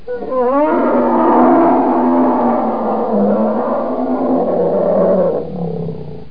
1 channel
bear.mp3